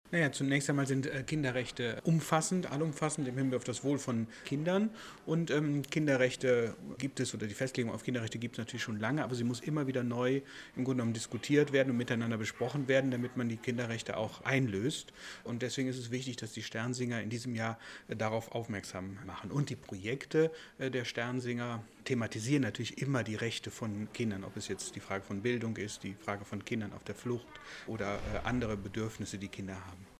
Pressemappe: Bundesweite Eröffnung in Paderborn - Pressekonferenz Audios